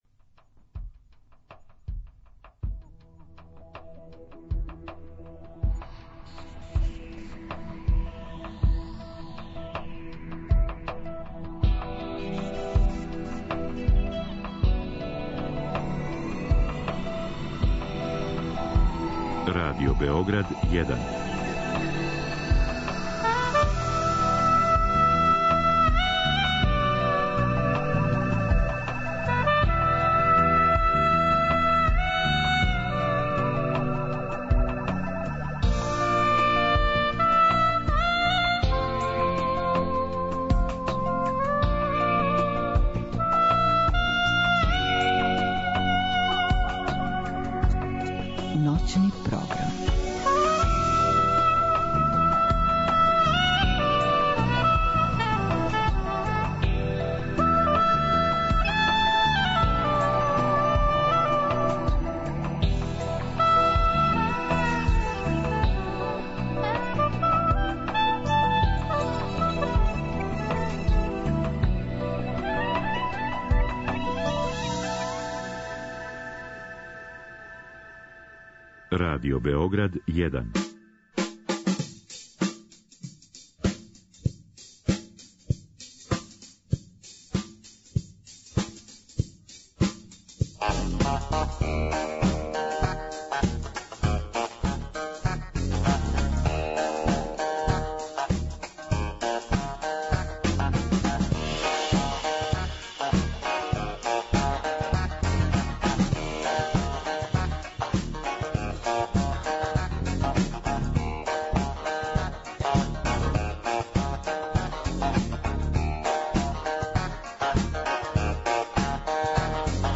sHpiritus movens – каравански бенд, бенд улице, бенд путева и тргова.